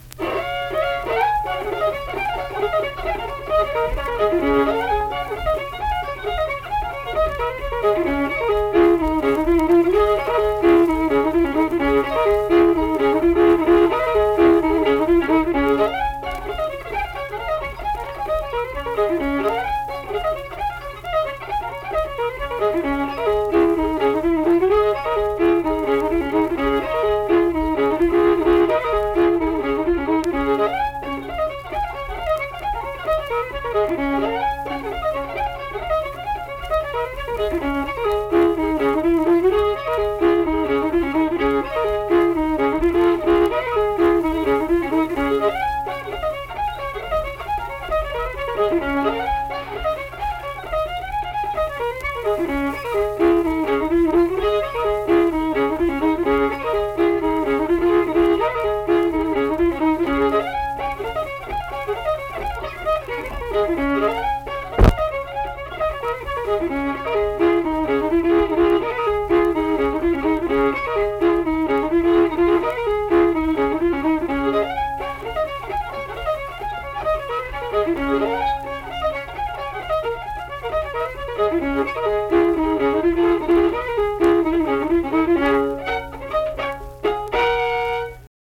Unaccompanied fiddle music
Instrumental Music
Fiddle
Flatwoods (Braxton County, W. Va.), Braxton County (W. Va.)